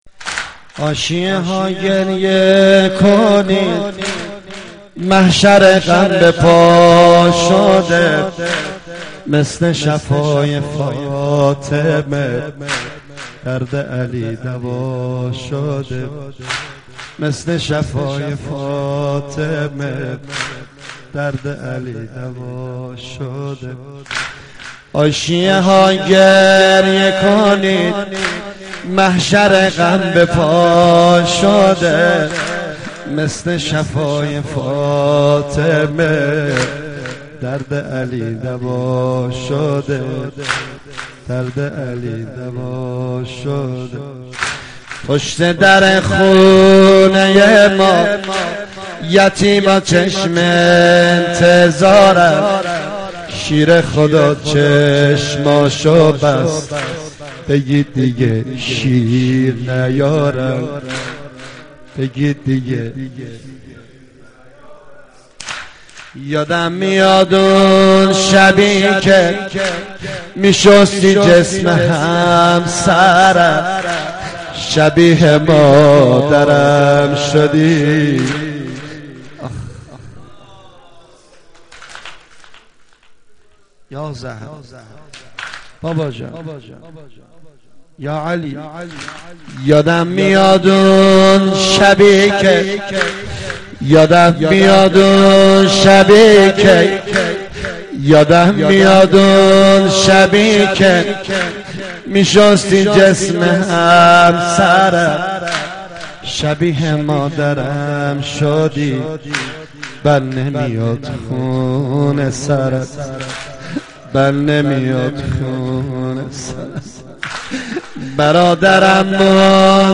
رمضان 89 - سینه زنی 2
رمضان 89 - سینه زنی 2 خطیب: نریمان پناهی مدت زمان: 00:06:40